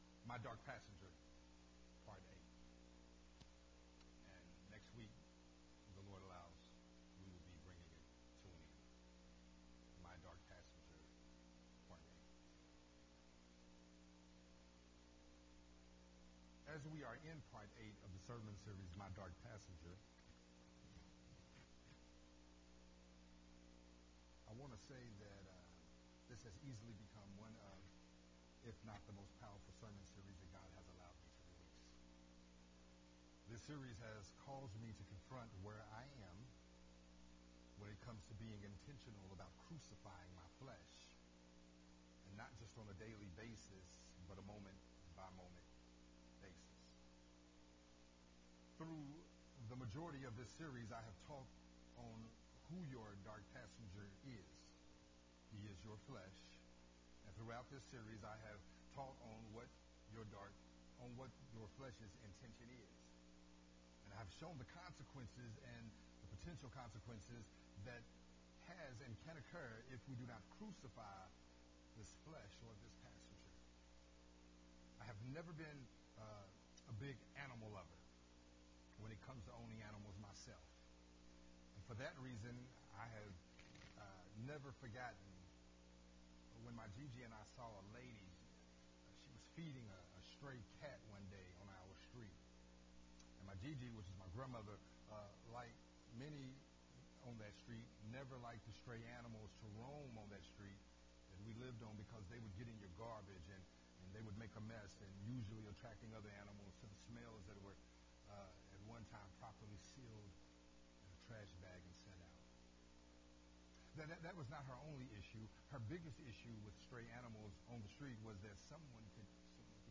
Sermon Series
recorded at Unity Worship Center on June 19th, 2022.